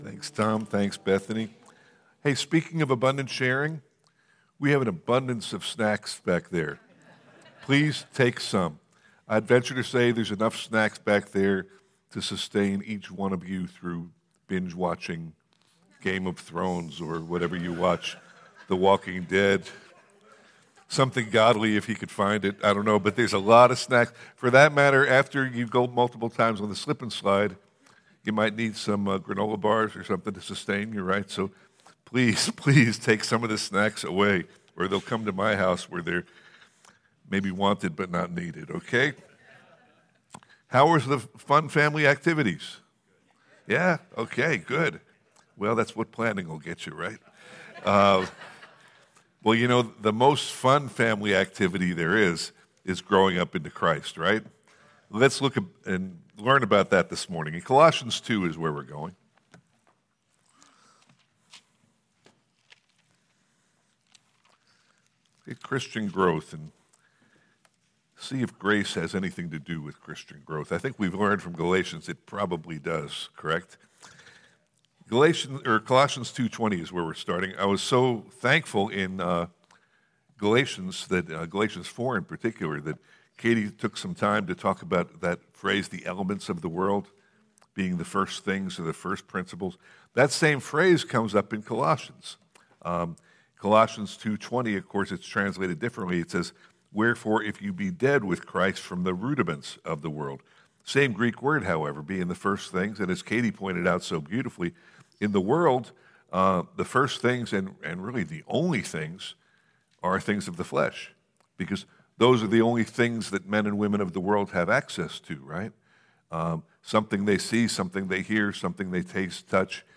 Closing Teaching